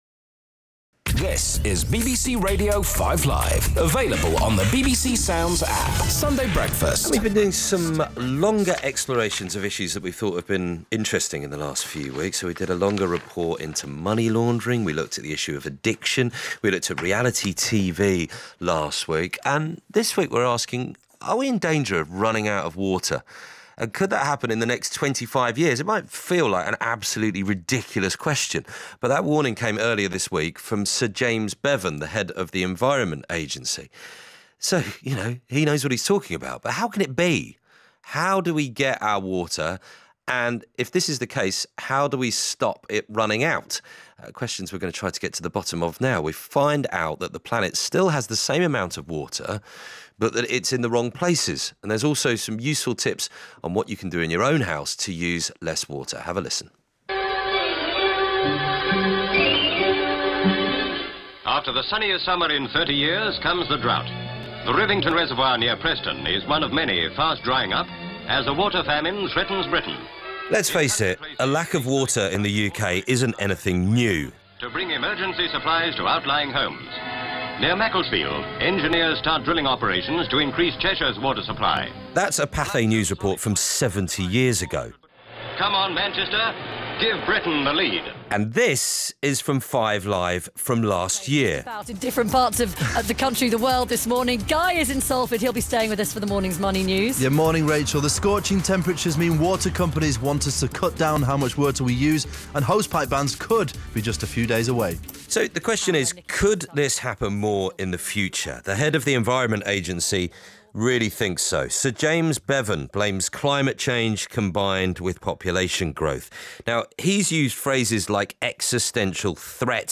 BBC Radio 5 Live interview about water shortage in England